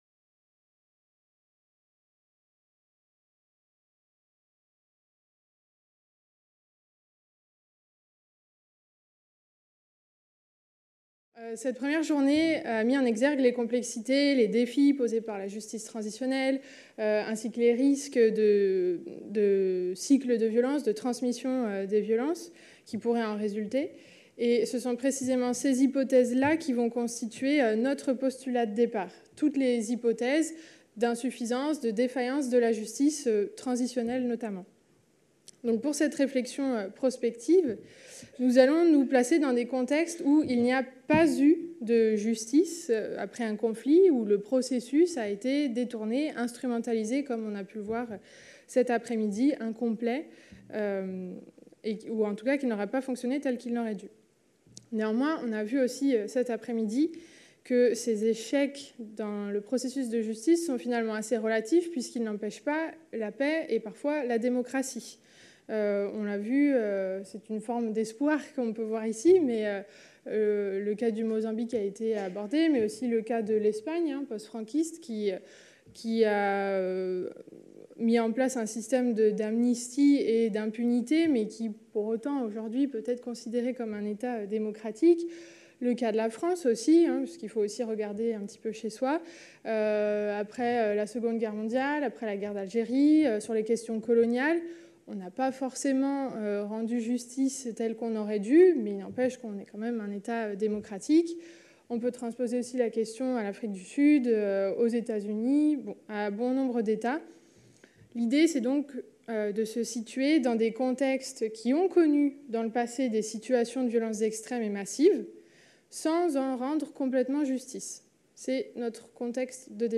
Cette communication a été prononcée dans le cadre du colloque international Justice, Vérité et Résilience(s) qui s'est tenu à Caen les 23 et 24 novembre 2018.